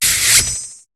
Cri de Scalpion dans Pokémon HOME.